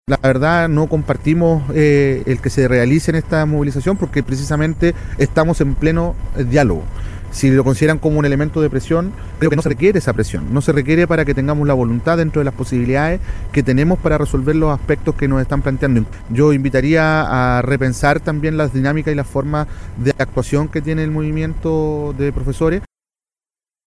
El ministro de Educación, Nicolás Cataldo, enfatizó a Radio Bío Bío que no comparte el llamado al estar en pleno diálogo. Además, mencionó que interrumpe el aprendizaje de los estudiantes.